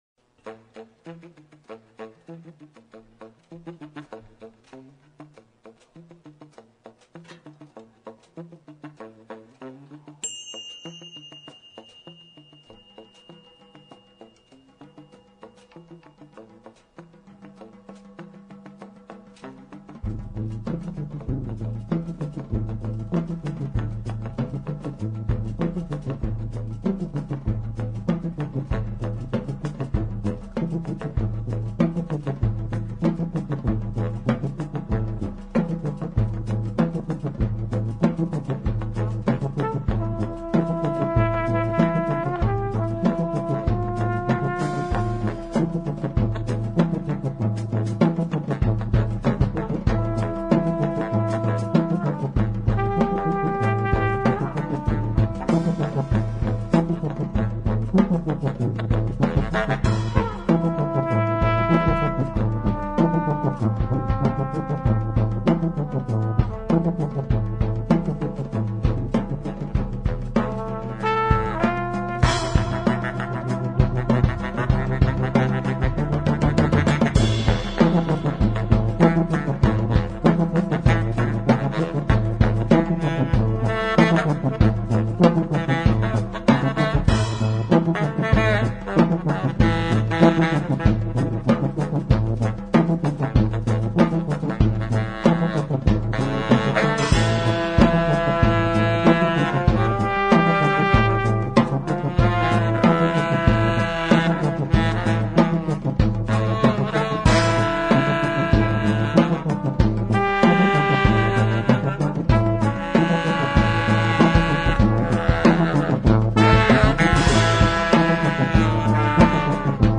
Enregistré en concert à la Mounéde (Toulouse, France)
saxs alto et baryton, mélodica, gueulophone.......
trompette, bugle, saxhorn baryton, mélodica, tambourin
soubassophone
batterie, bidules.